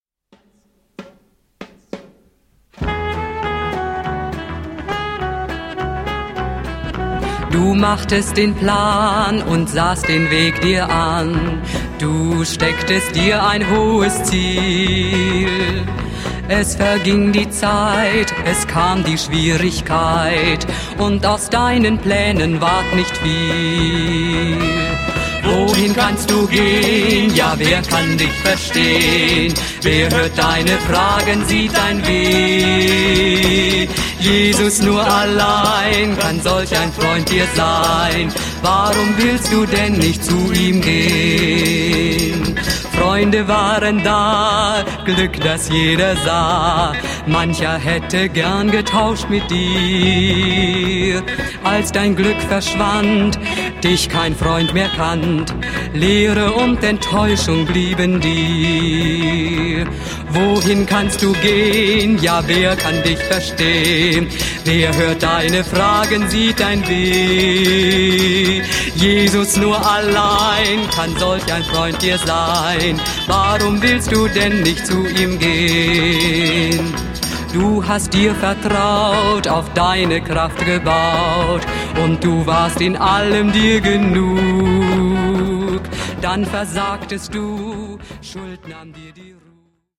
Unverkennbar - die ausdrucksvolle Stimme
Pop